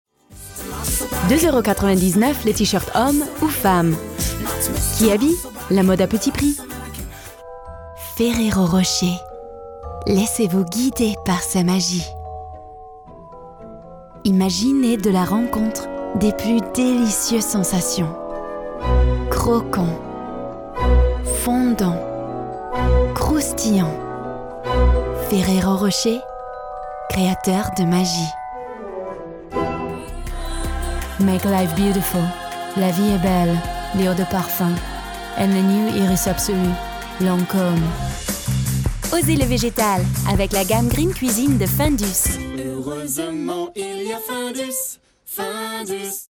Female
Bright, Character, Cheeky, Children, Confident, Cool, Friendly, Natural, Soft, Versatile, Young, Engaging, Warm
Her voice is relatable, contemporary and youthful with a warm and textured sound.
Microphone: Sennheiser MKH 416, Rode NT1-A
Audio equipment: Session Booth, Scarlett Focusrite 2i2, Beyerdynamic DT770 Pro